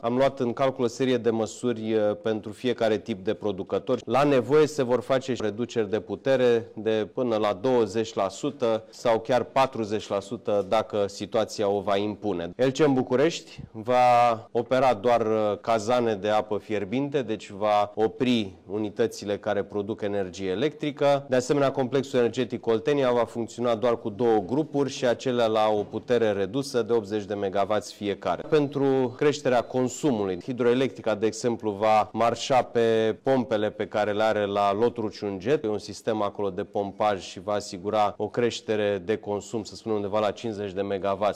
Ministrul Sebastian Burduja a subliniat că autorităţile sunt pregătite şi nu există risc de întreruperi majore de curent: